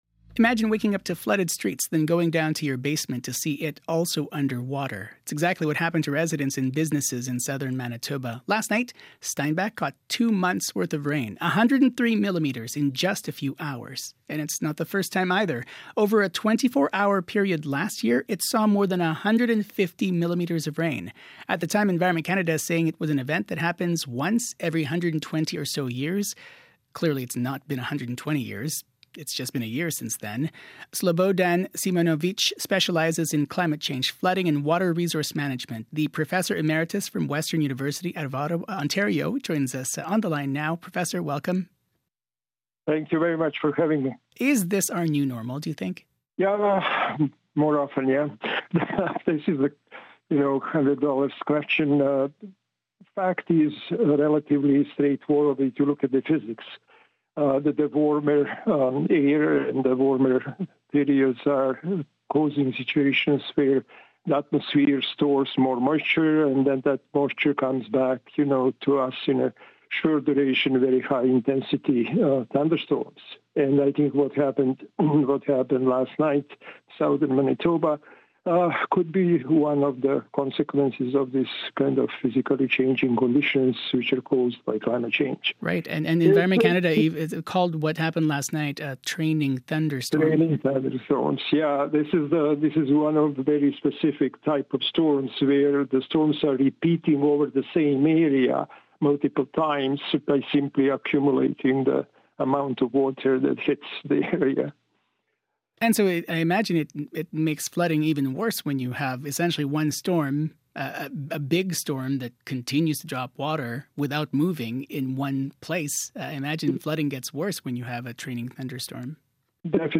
• CBC Radio Manitoba interview (2025). [1]